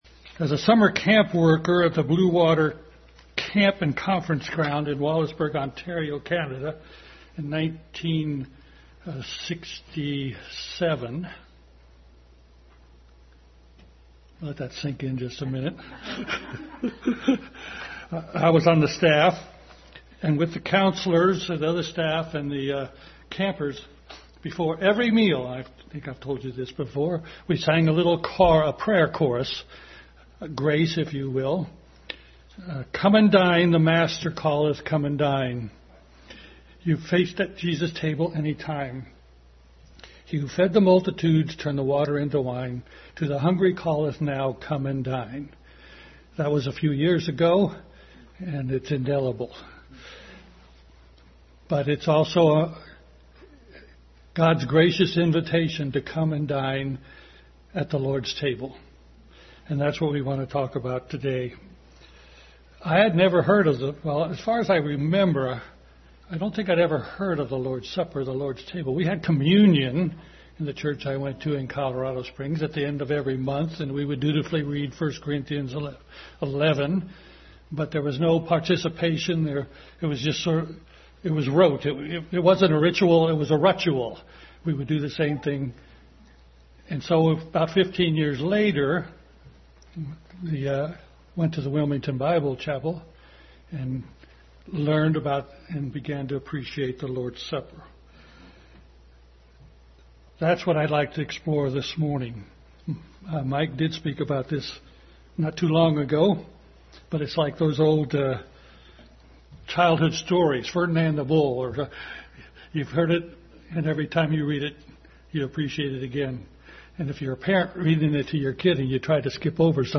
Family Bible Hour Message.
Various Scriptures Service Type: Family Bible Hour Family Bible Hour Message.